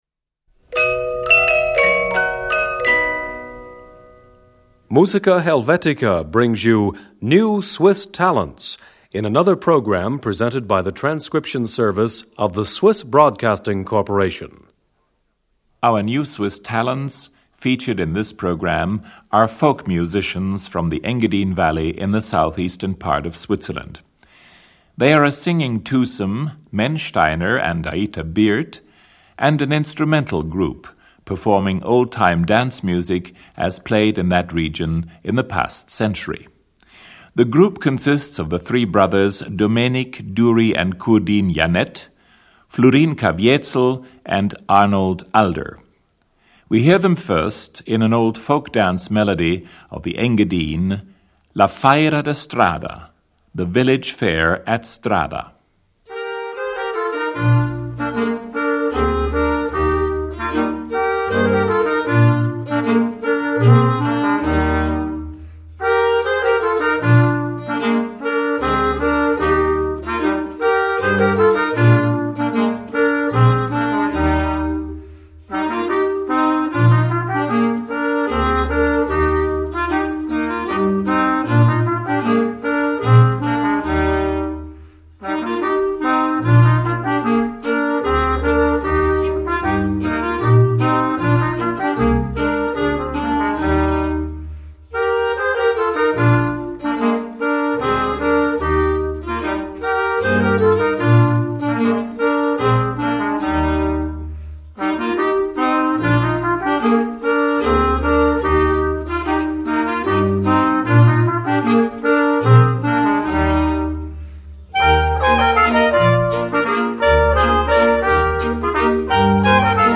(Traditional Dance)
Valser alla veglia (Waltz).